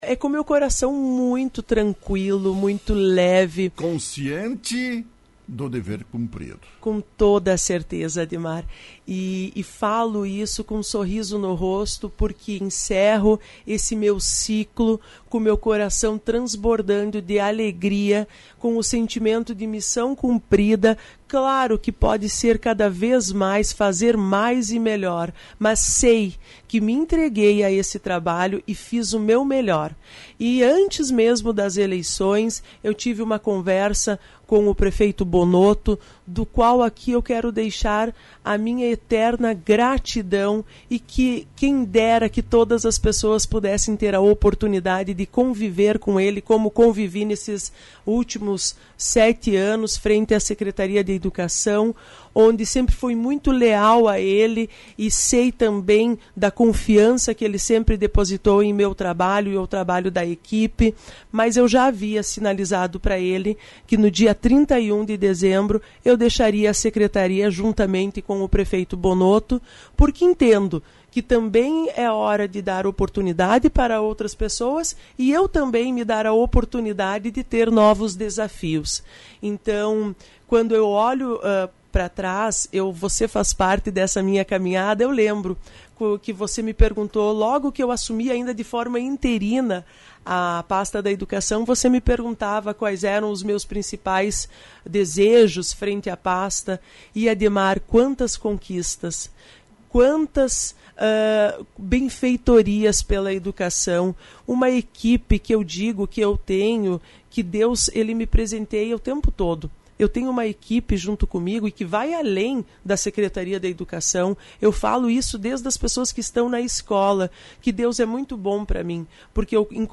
Secretária municipal da Educação, Cultura e Desporto, Aline Teles da Silva, entrevistada pela Rádio Lagoa FM nesta quinta-feira, afirmou que deixa a pasta em 31 de dezembro. Não continuará no cargo.